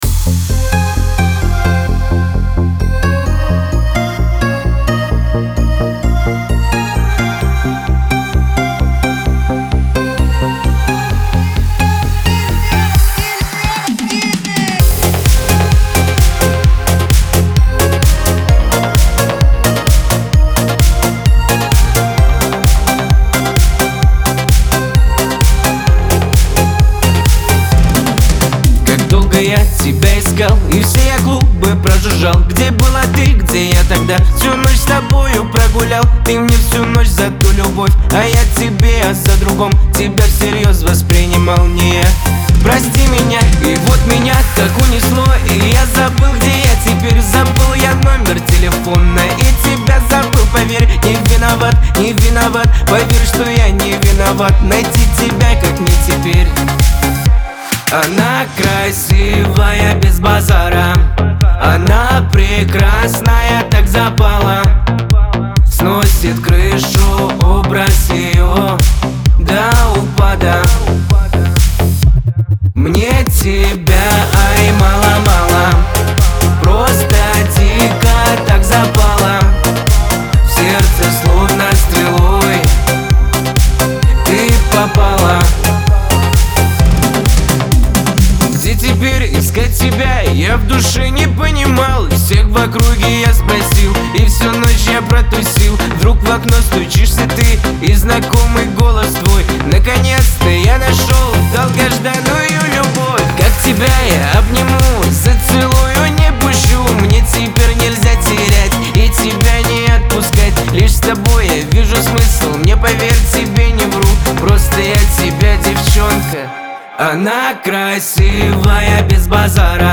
диско , pop
эстрада